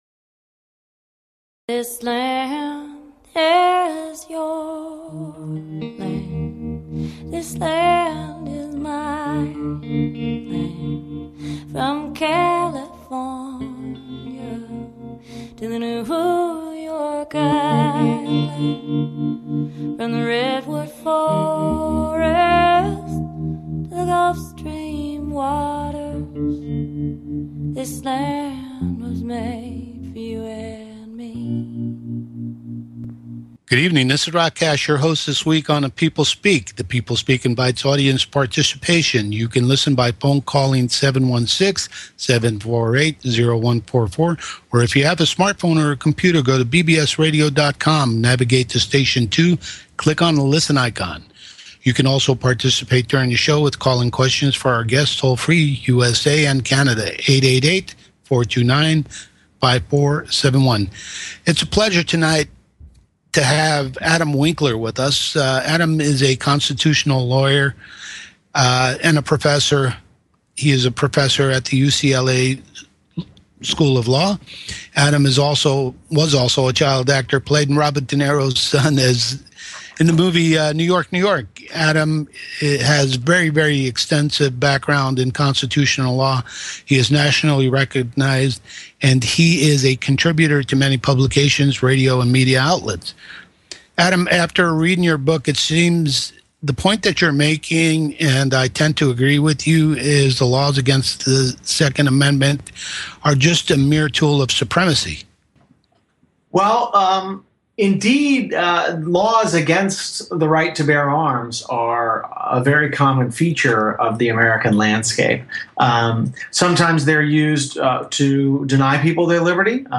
Talk Show Episode, Audio Podcast, CONSTITUTIONAL LAWYER ON GUN CONTROL and Guest Professor Adam Winkler UCLA on , show guests , about Professor Adam Winkler UCLA,GUNFIGHT: Gunfight: The Battle Over the Right to Bear Arms in America, categorized as Education,News,Politics & Government
Guest, Adam Winkler